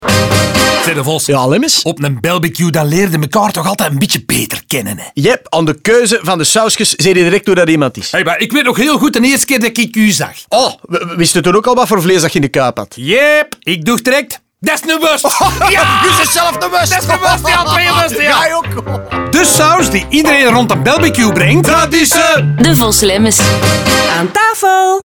Maar geen Devos & Lemmens campagne zonder een rijk assortiment radiospots natuurlijk. Ook daar vormen de verschillende types een rode draad doorheen de spots.